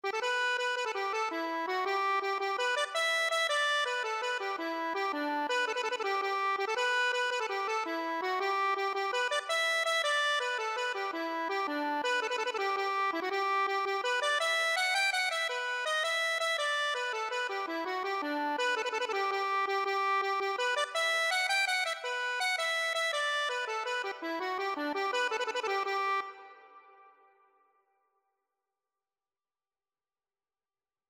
Traditional Music of unknown author.
G major (Sounding Pitch) (View more G major Music for Accordion )
9/8 (View more 9/8 Music)
Accordion  (View more Easy Accordion Music)
Traditional (View more Traditional Accordion Music)